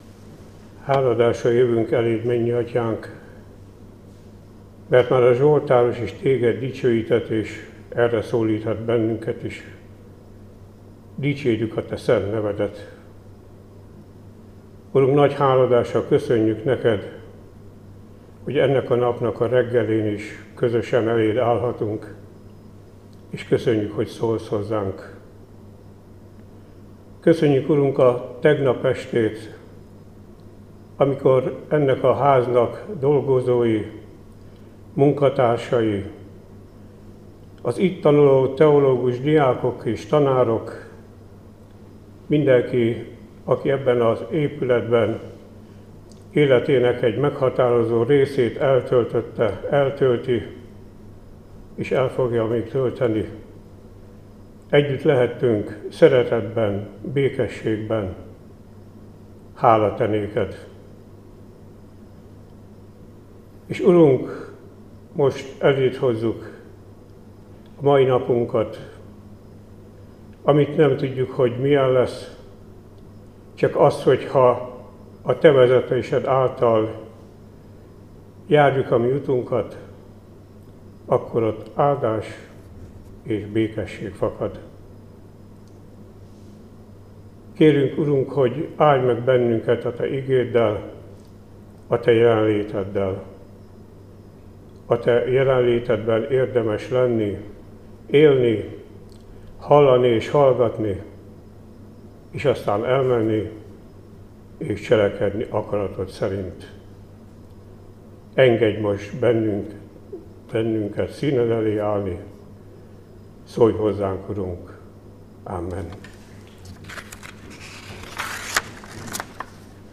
Áhítat, 2025. május 13.